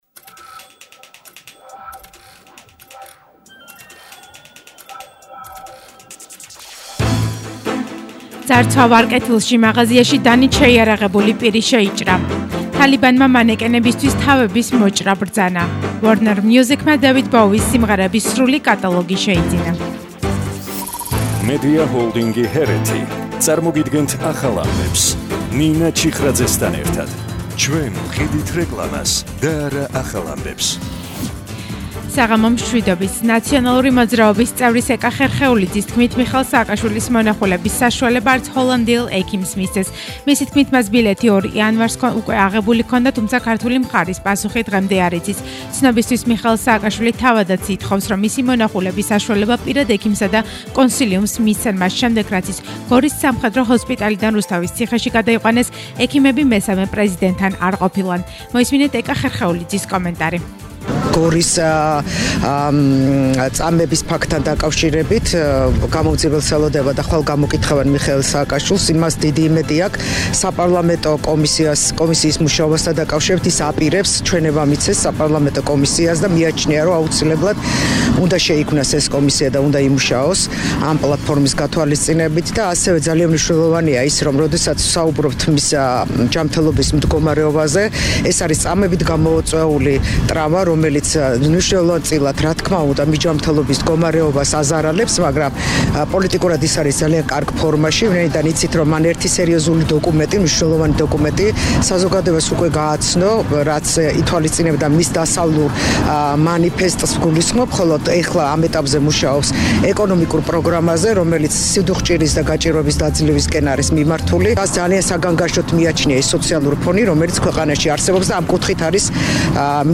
ახალი ამბები 19:00 საათზე – 04/01/22